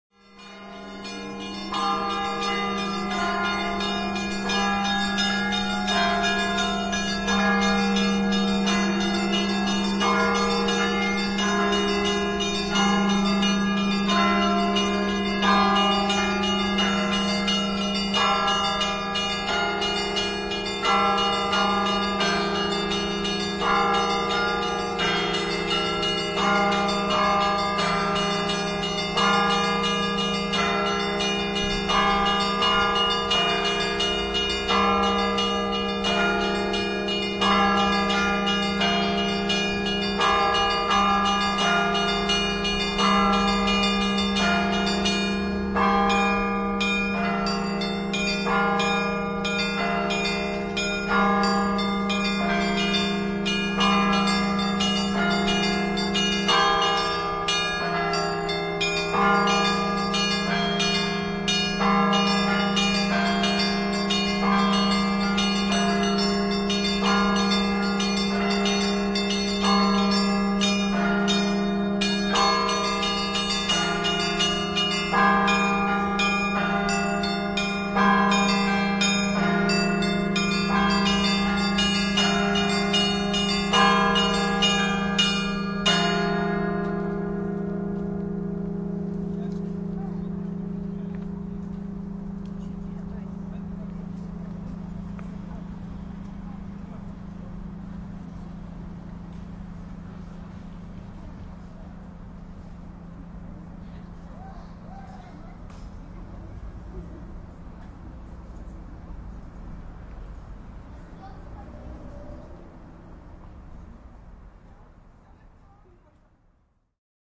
Звуки колокола
Звонят колокола